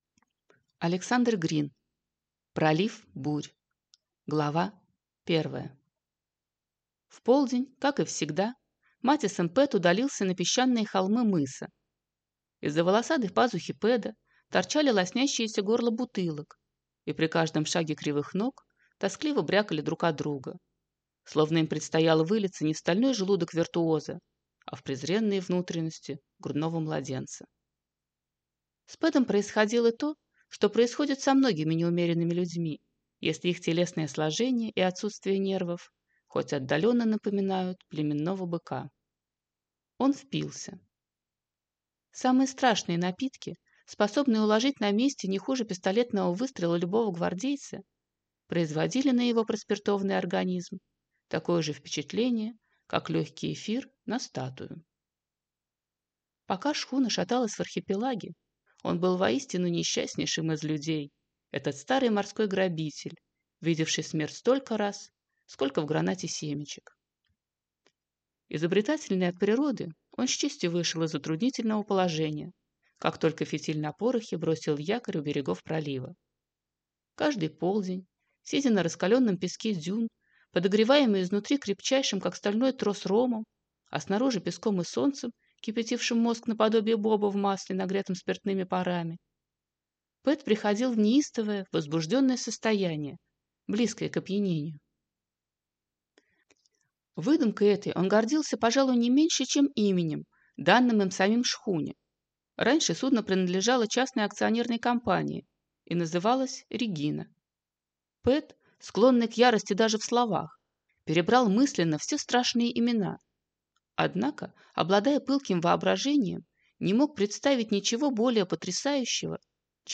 Аудиокнига Пролив бурь | Библиотека аудиокниг